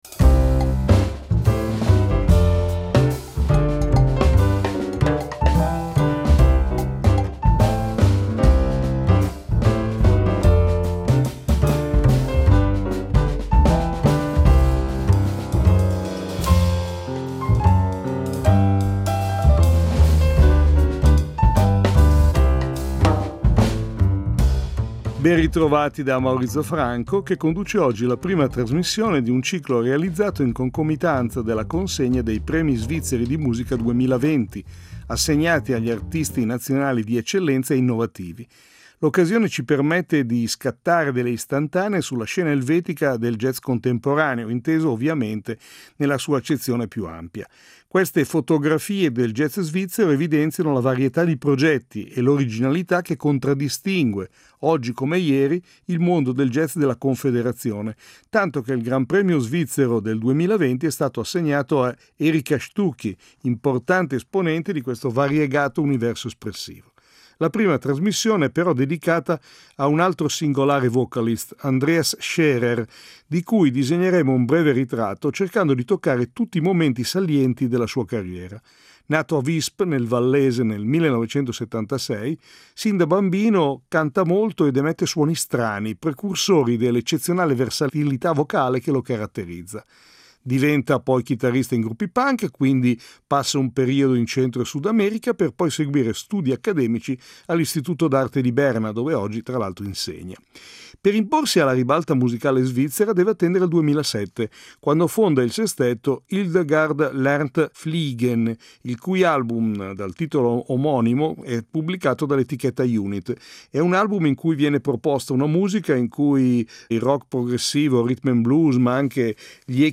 il cantante, vocalist e beatboxer Andreas Schaerer